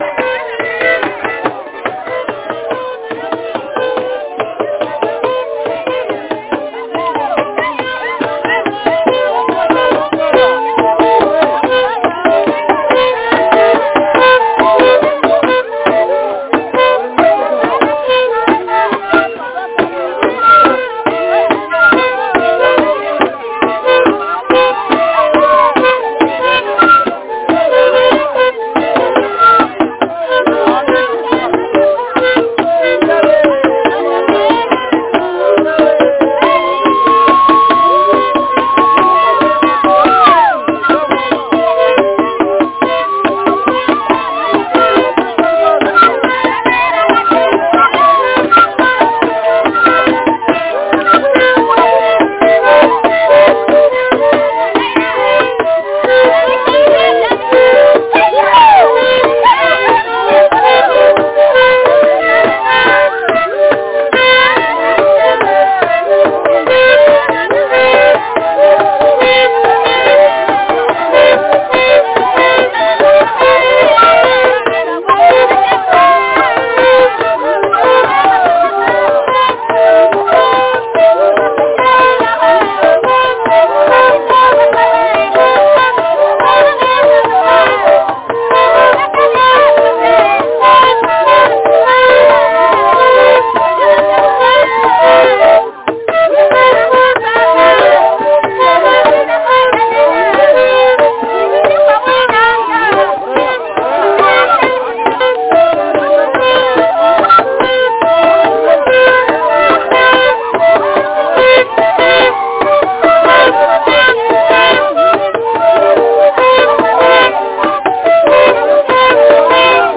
Ce sont les chants de danse traditionnelle.